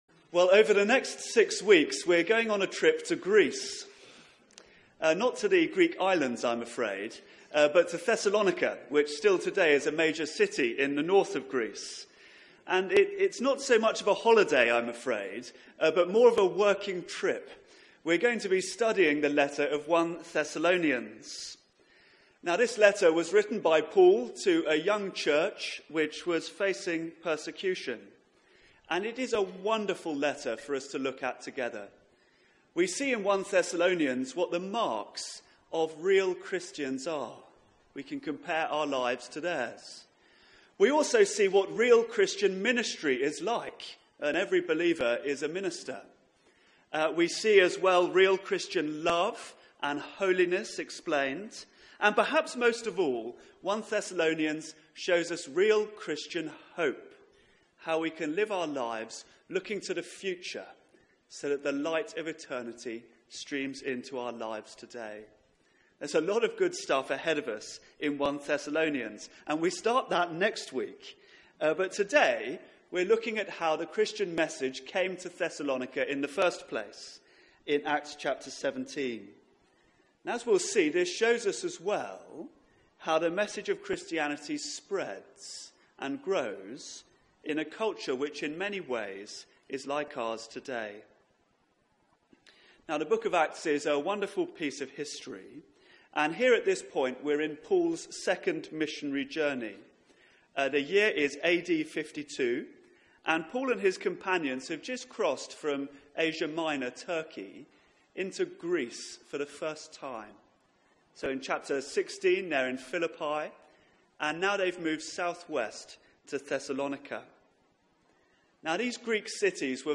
Media for 9:15am Service on Sun 14th May 2017
Theme: Real Christian Beginnings Sermon (11:00 Service)